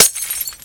Add another glass breaking sound
default_break_glass.4.ogg